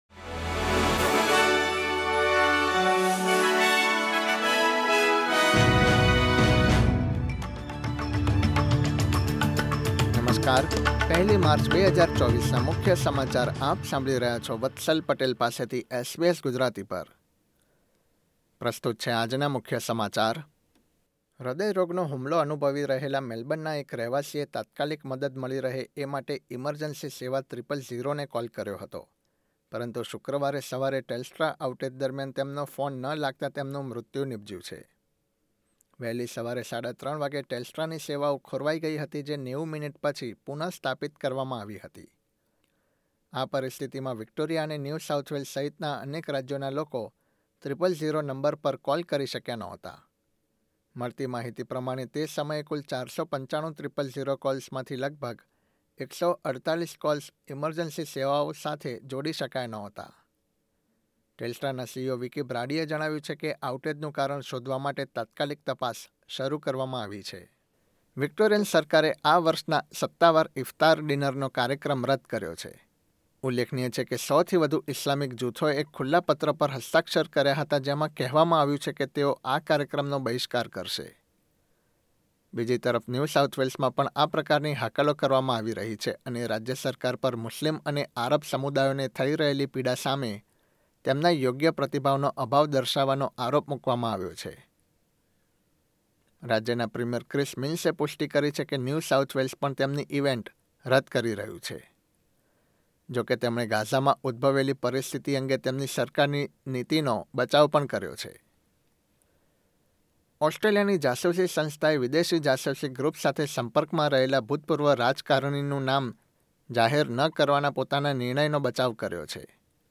SBS Gujarati News Bulletin 1 March 2024